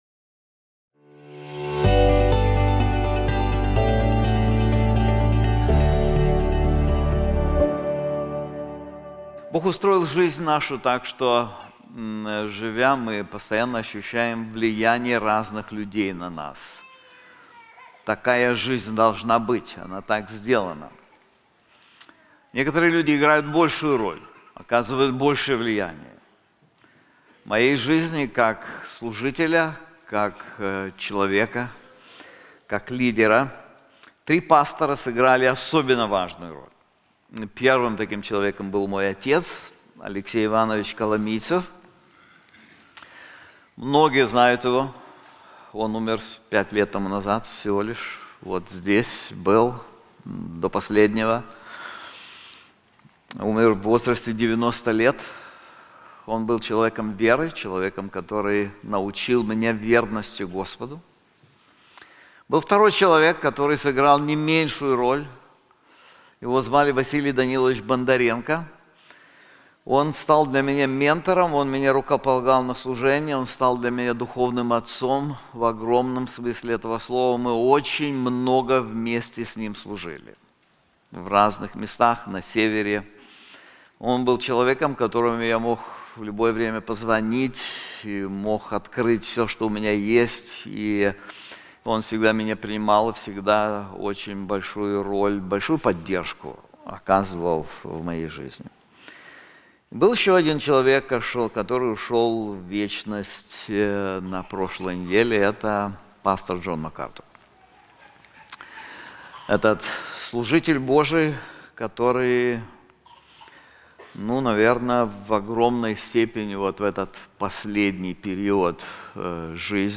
This sermon is also available in English:Life of a Pastor • Hebrews 13:7-8